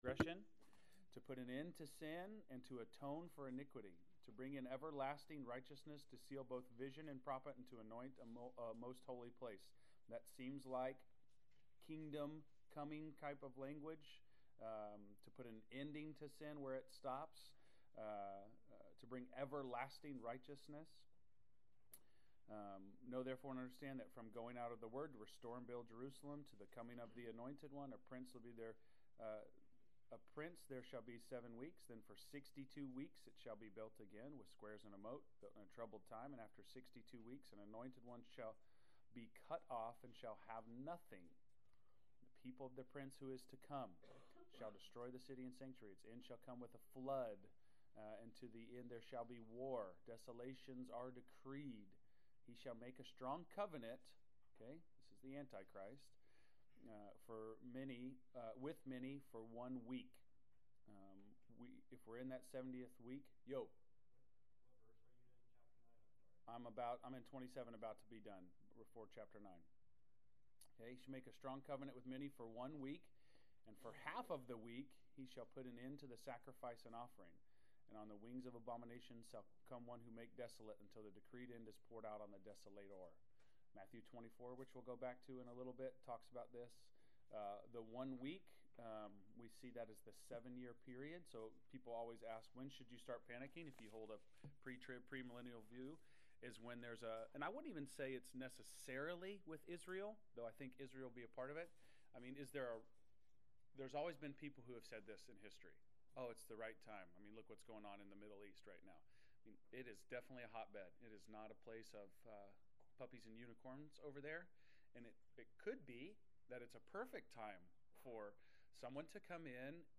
Class Session Audio March 04